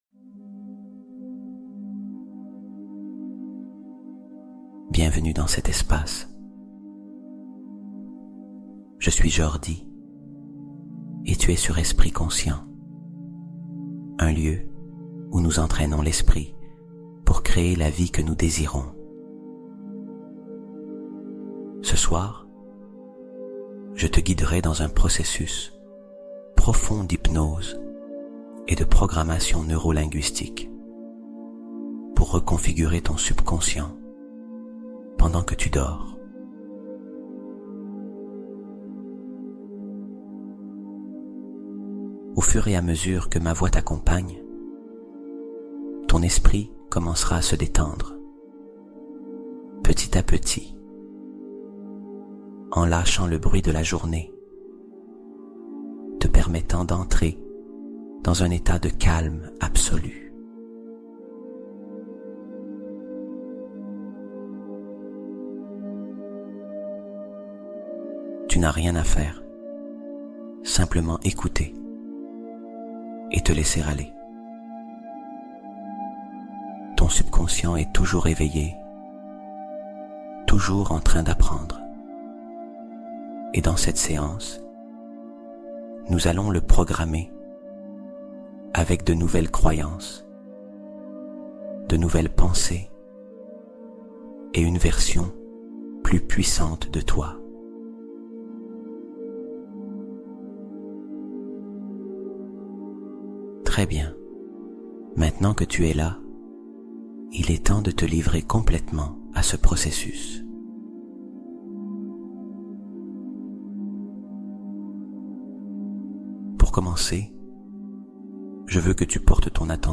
Transformation : Hypnose de reprogrammation profonde pour un nouveau départ